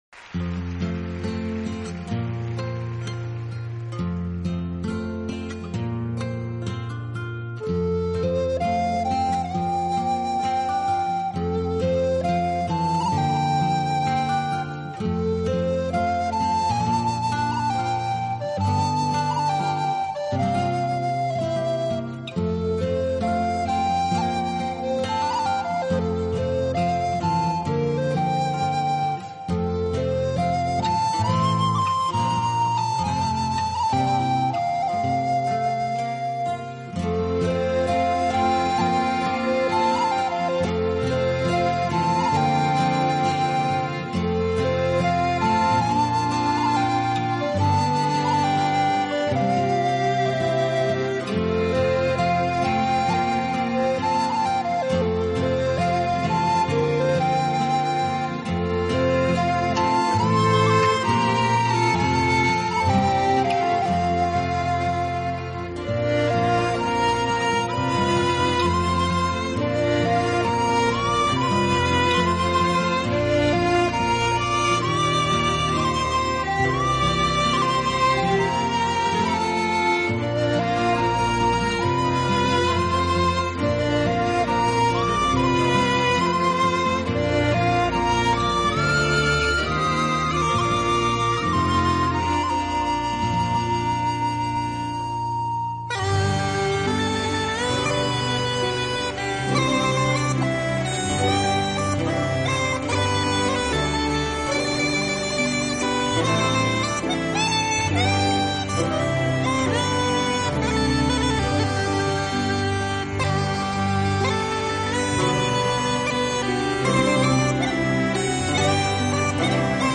风笛专辑